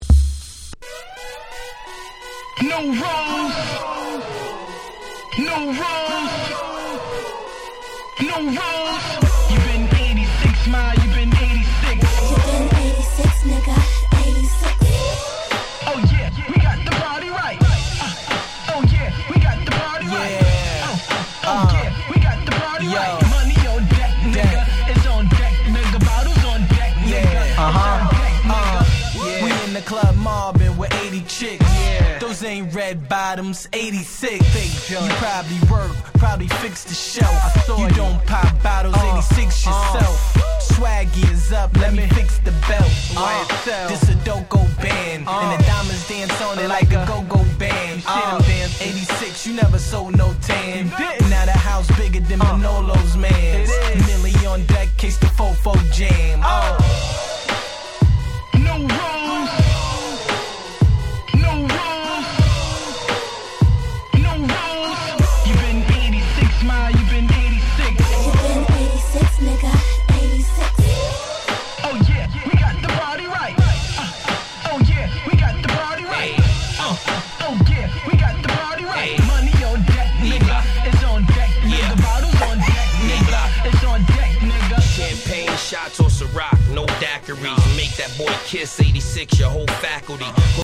11' Smash Hit Hip Hop !!